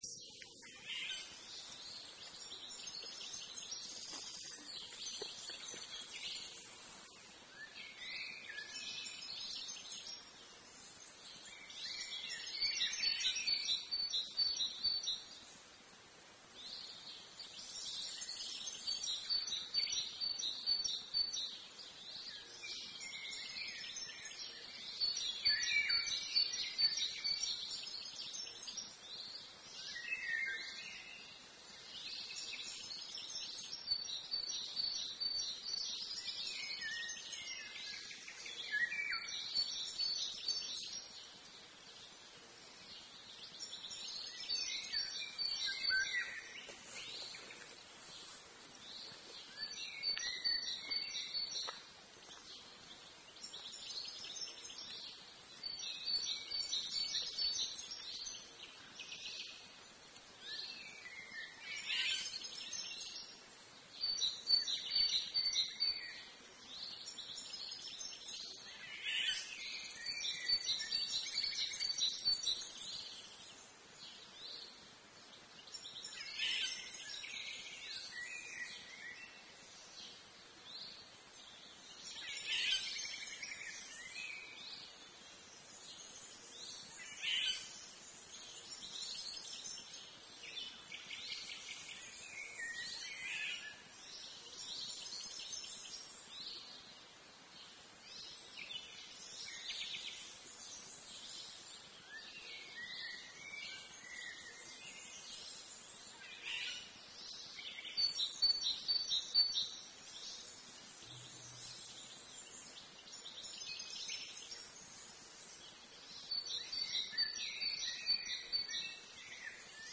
Si no has podido venir a Blesa este mes de mayo, aquí te ofrecemos cuatro minutos y medio de relajación, escuchando a los pájaros del Aguasvivas en la arboleda de la estación de aforos, junto al puente nuevo... como si fuese un despreocupado sábado por la mañana.
Naturaleza en el Aguasvivas: mirlos, ruiseñores bastardos, palomas, herrerillo, quizás una rana, quizá un reloj. (1 MB)